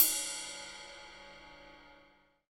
CYM RIDE402L.wav